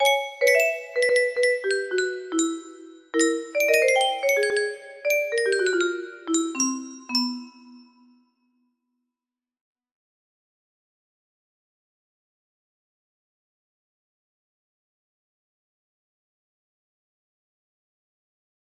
Jazzy music box melody